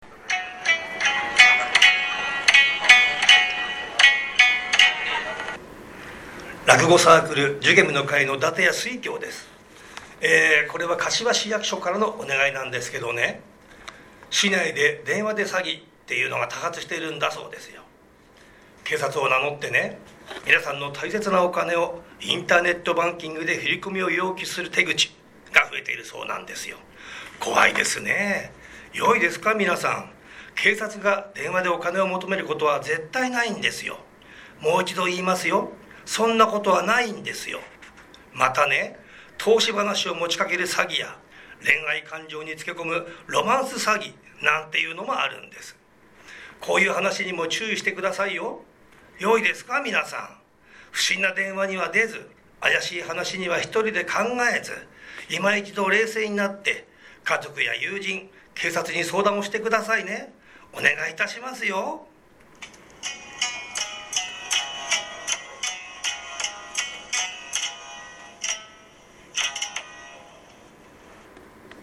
4月15日（水曜日）より、もっと身近に防犯を意識してもらえるような、思わず耳に残る、やさしくて分かりやすい音声を使用します。
アマチュア落語家